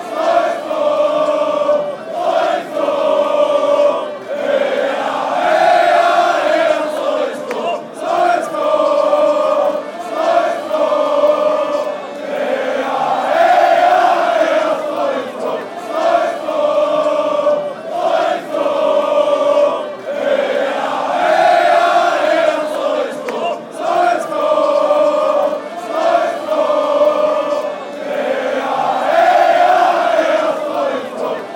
A Slovakia soccer chant.